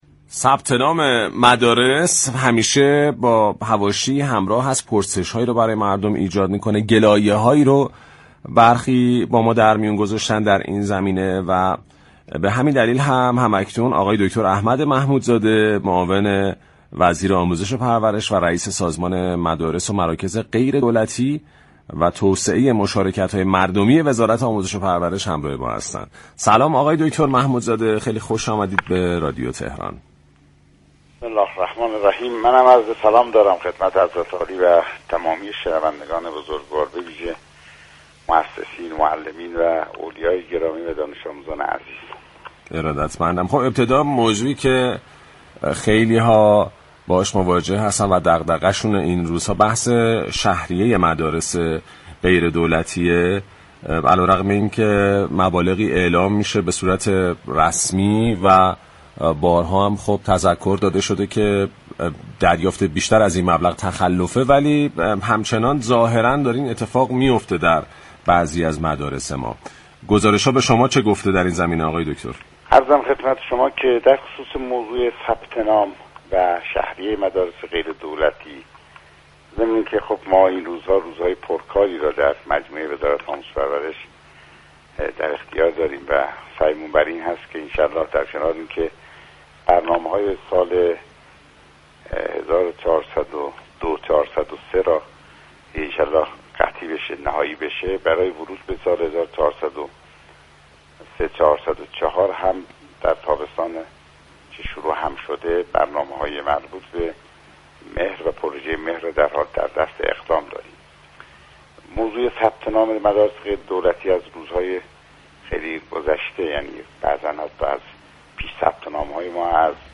به گزارش پایگاه اطلاع رسانی رادیو تهران، احمد محمودزاده رئیس سازمان مدارس و مراكز غیر دولتی و توسعه مشاركت‌های مردمی در گفت و گو با «شهر آفتاب» درخصوص شهریه مدارس غیر دولتی كشور اظهار داشت: شهریه مدرسه به مدرسه و مركز به مركز براساس 7 شاخص (دوره تحصیلی، كیفیت آموزش و پرورش، فضا و امكانات و تجهیزات آموزشی و غیرآموزشی، تورم اقتصادی سال، موضوعات مربوط به نیروی انسانی و فوق برنامه‌ها) محاسبه می‌شود.